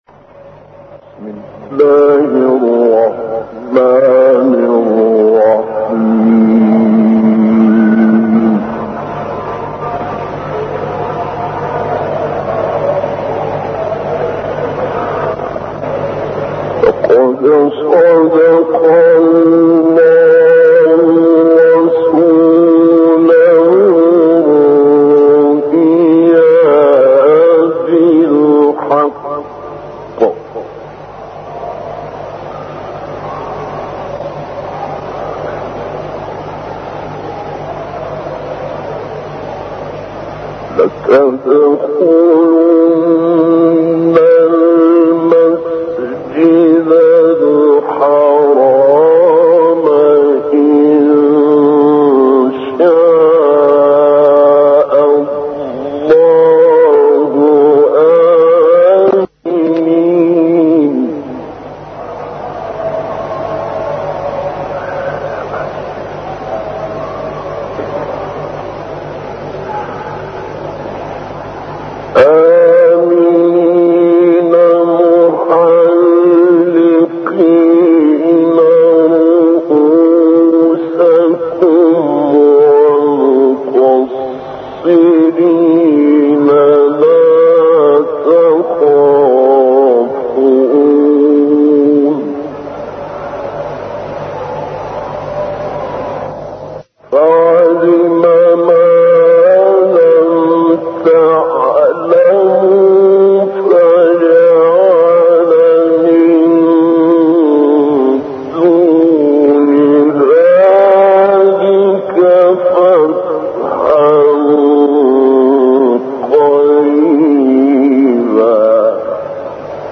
گروه فعالیت‌های قرآنی: تلاوتی قدیمی با صوت ابوالعینین شعیشع که پیش از پیروزی انقلاب اسلامي در مسجد گوهرشاد اجرا شده است، ارائه می‌شود.